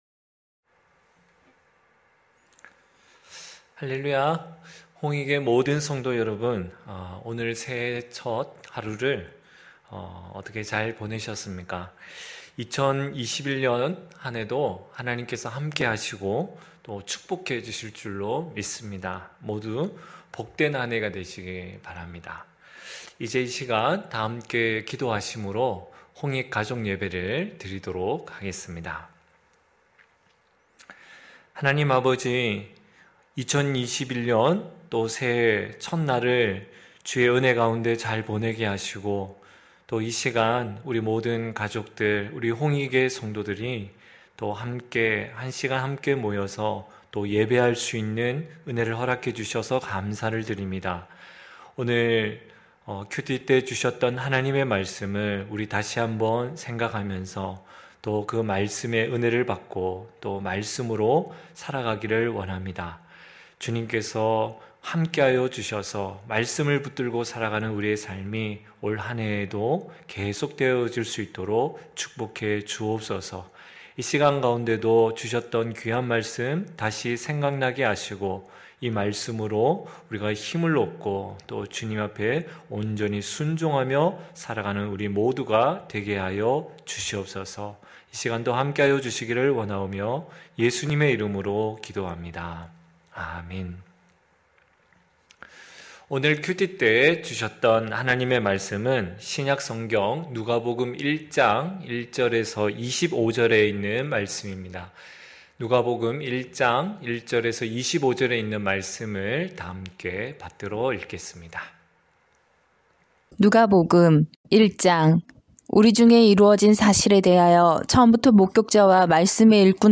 9시홍익가족예배(1월1일).mp3